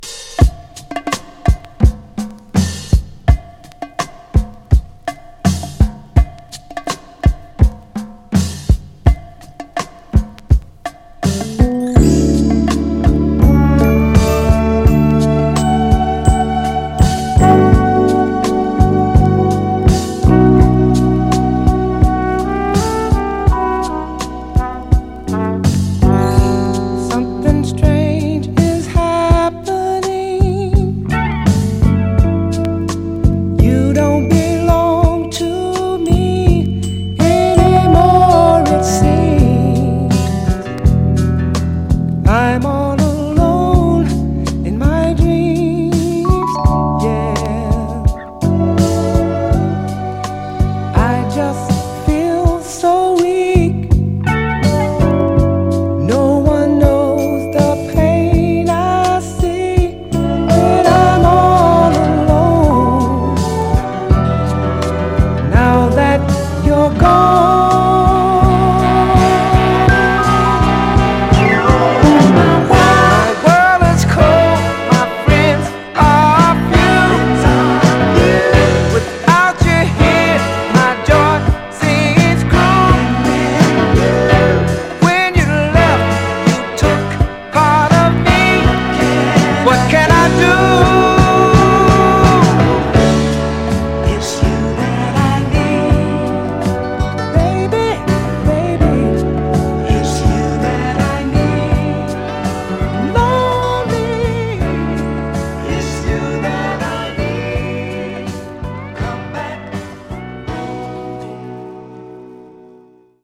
サンプリングネタにもなったメロウ・ソウル/バラード名曲です！